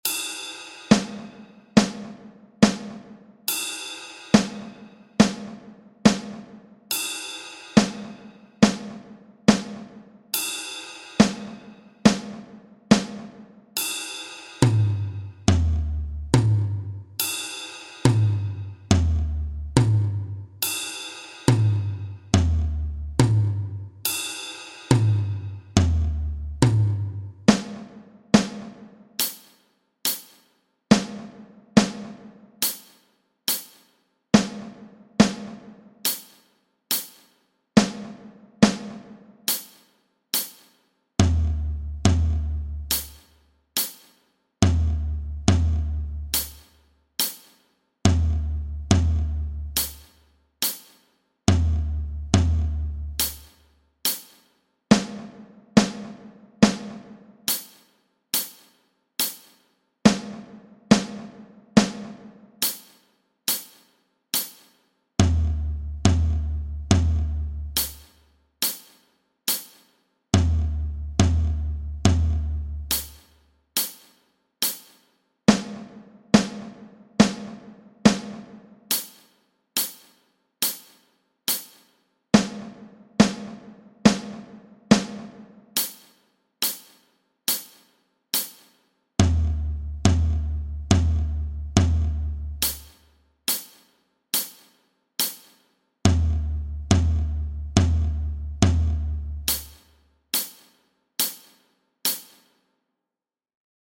Hier gibt es die kompletten Noten als pdf-Datei und ein Hörbeispiel als mp3-Datei:
Übungen für die Hände - Teil 2.mp3